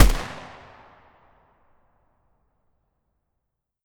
AR2_Shoot 02.wav